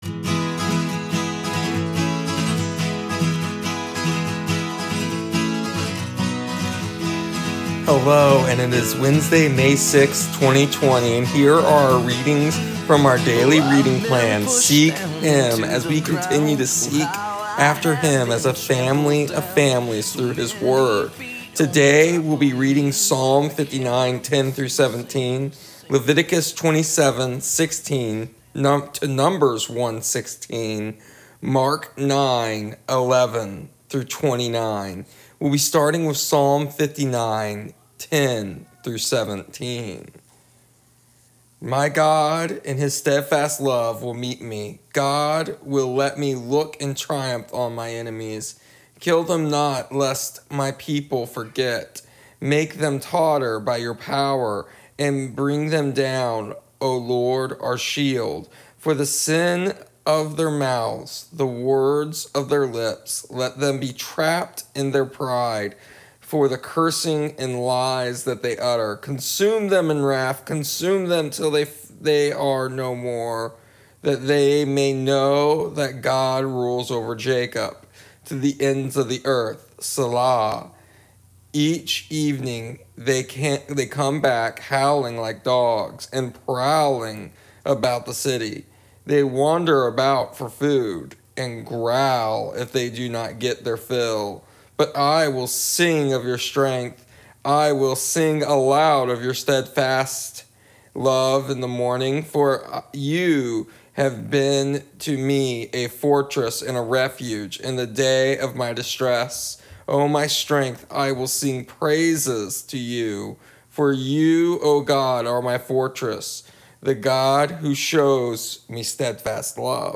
Here is today’s audio readings from our daily reading plan seek Him. Today we discuss the nature and purpose of the book of Leviticus as we finish Leviticus and move to numbers.